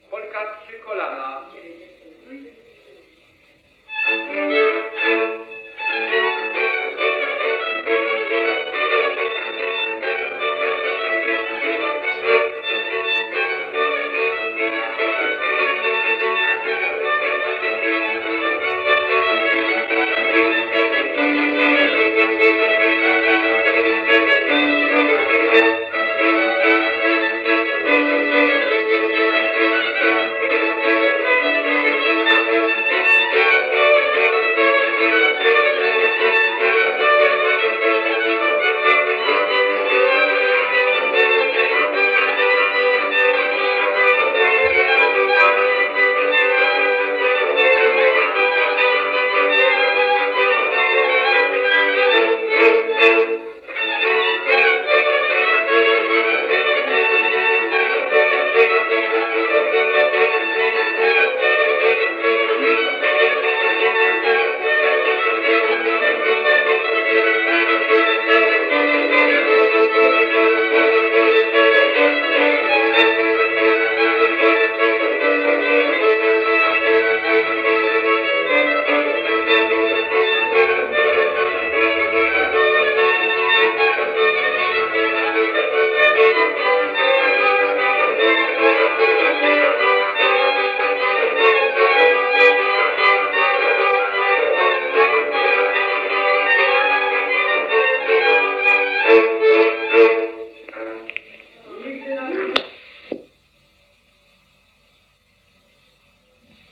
Instrumentalny 25 (Polka prztykolano) – Żeńska Kapela Ludowa Zagłębianki